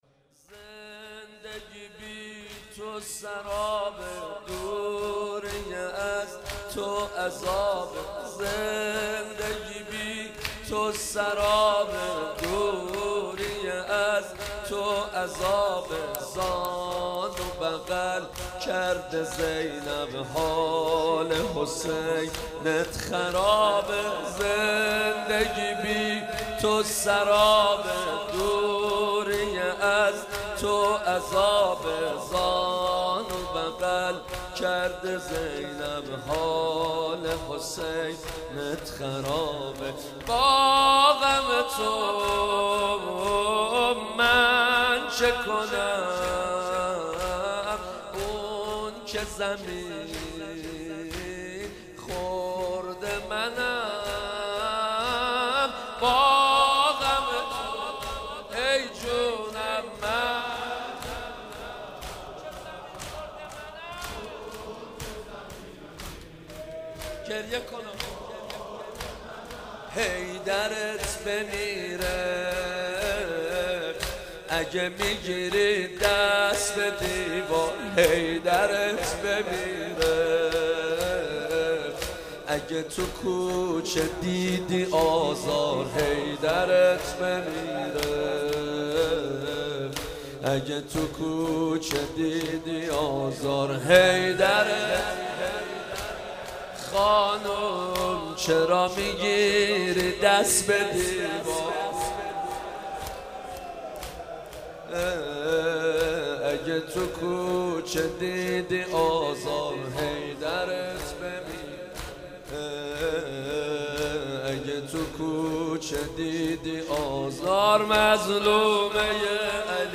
مداح
مناسبت : شهادت حضرت فاطمه زهرا سلام‌الله‌علیها
قالب : زمینه